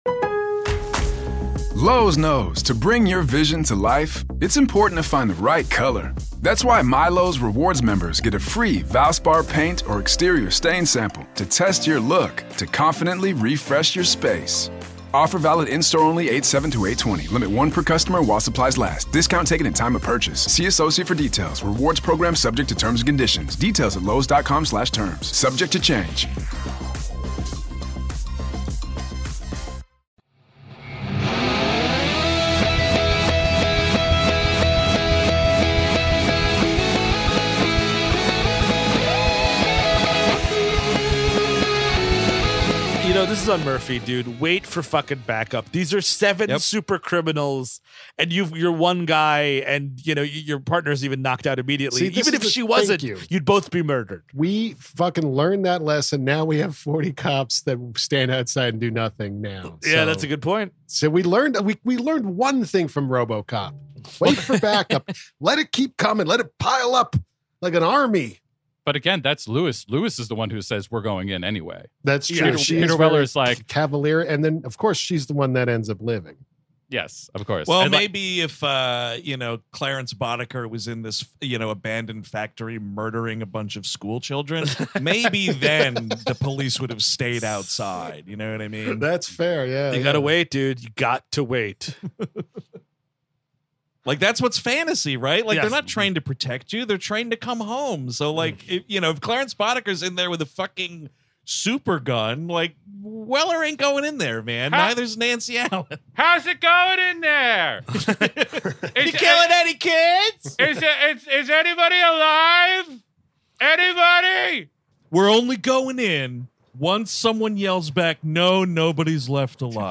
On this month's WLM, the guys are chatting about one of the absolute best films about a dead guy who gets turned into a robot, Paul Verhoeven's Robocop! Has there been a smarmier, nastier villain than the great Kurtwood Smith's portrayal of Clarence Boddicker?